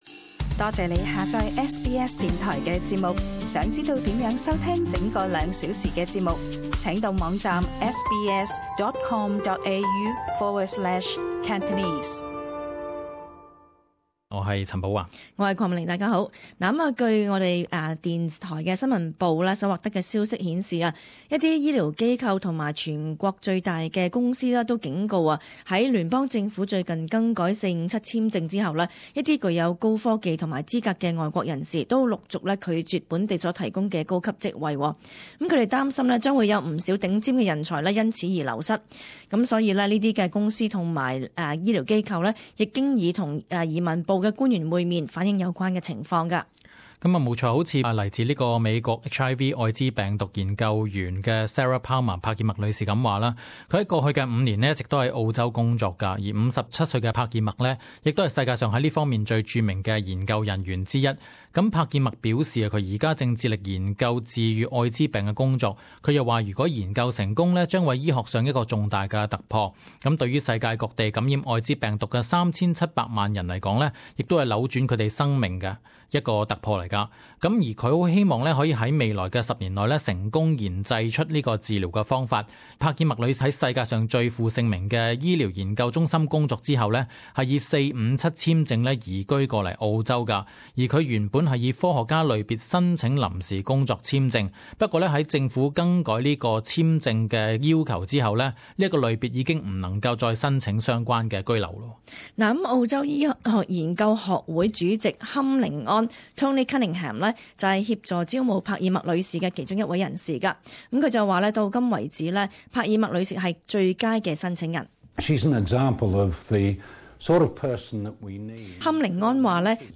【時事報導】457 簽證令澳洲公司流失高層人材？